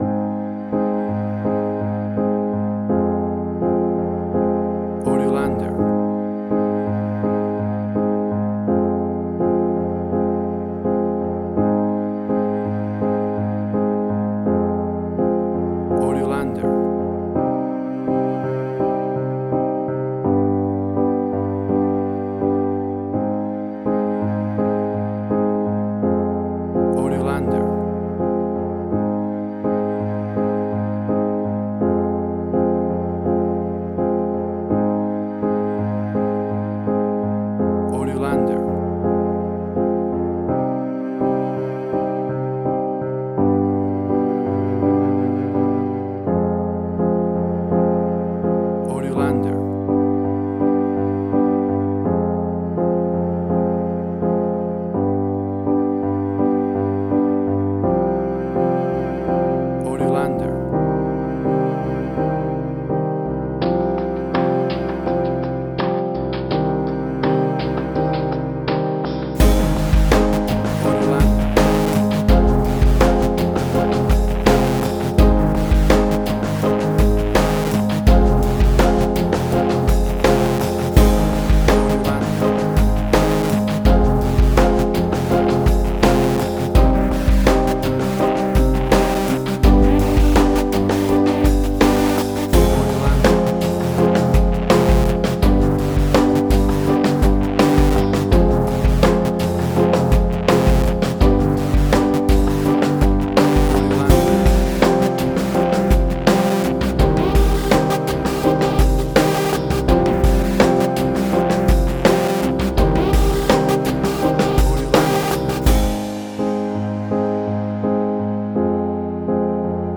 Suspense, Drama, Quirky, Emotional.
Tempo (BPM): 83